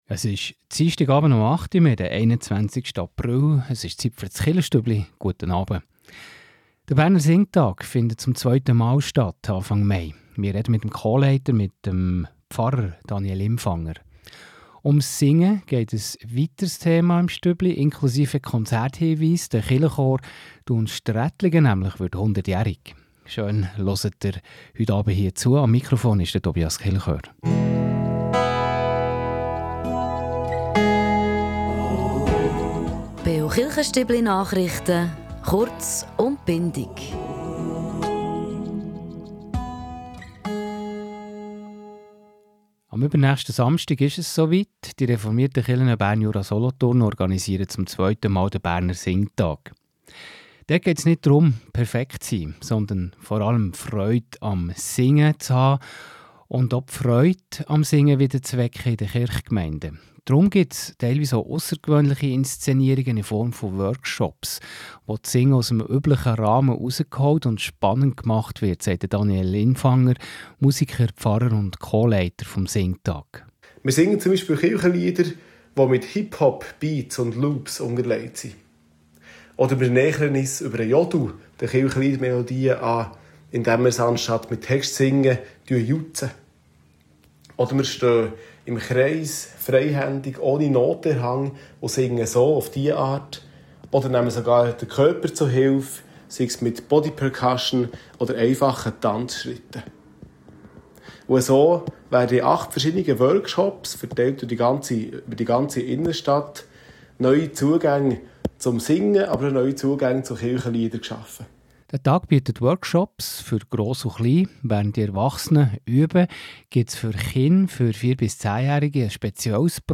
In dieser Ausgabe des Beo Chilchestübli wird es laut, kreativ und alles andere als verstaubt. Wir blicken auf den 2. Berner Singtag, bei dem die reformierten Kirchen Bern-Jura-Solothurn beweisen, dass Kirchenlieder und moderne Beats perfekt zusammenpassen.